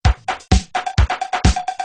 Free MP3 LinnDrum - LM1 & LM2 - Loops 4